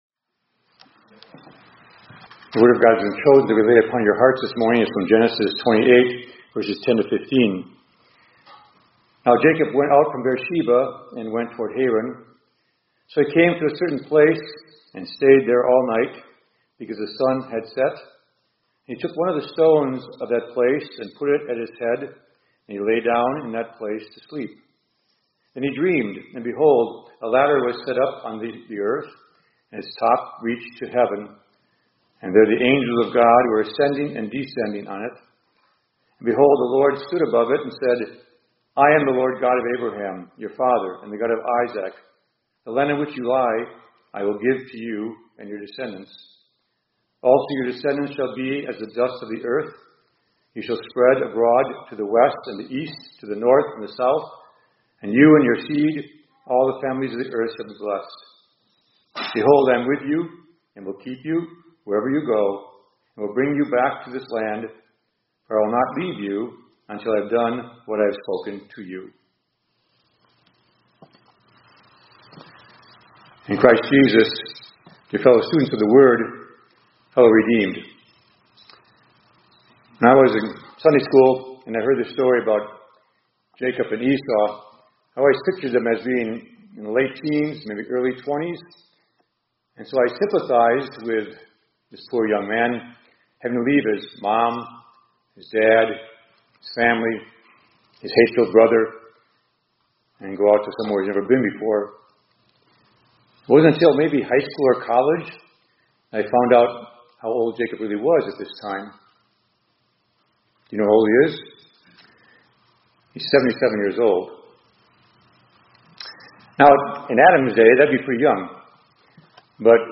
2025-04-14 ILC Chapel — Jesus Meets Us at the Bottom of the Ladder